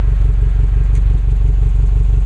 aidol.aif